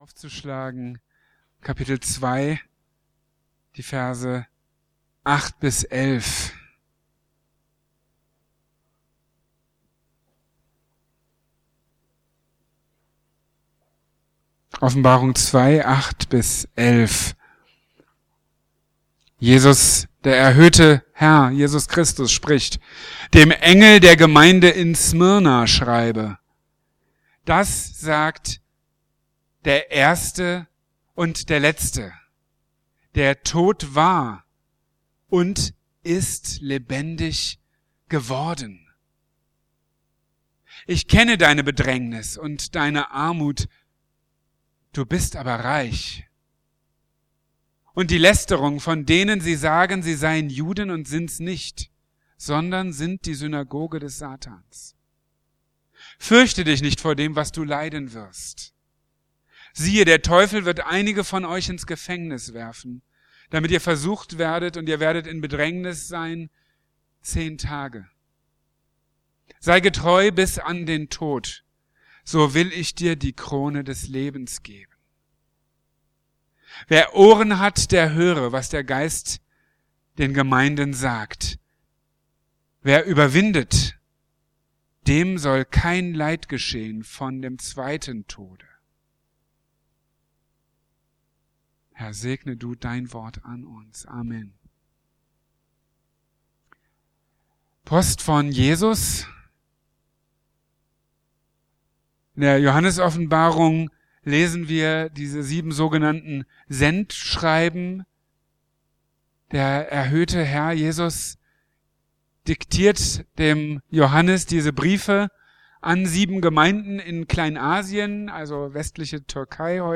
Treu bis zum Tod | Marburger Predigten